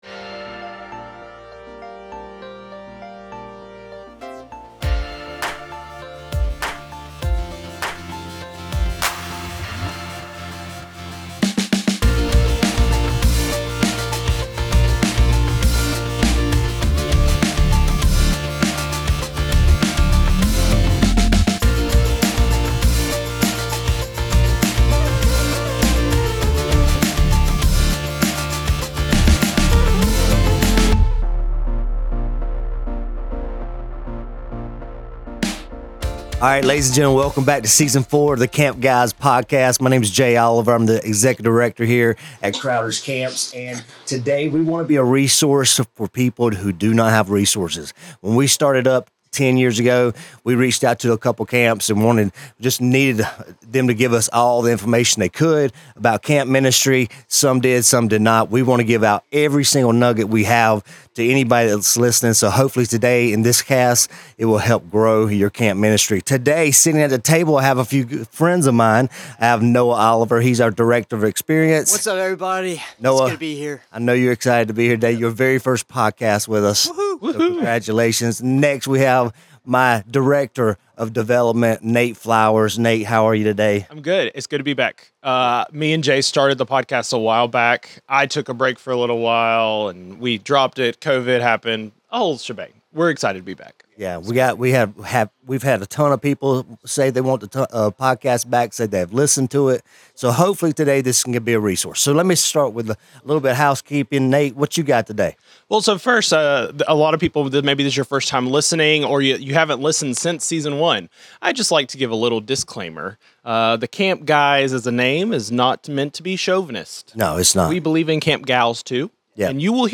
Ask yourself "Why not us?" as we dig into what may be holding you back in this lightning round conversation.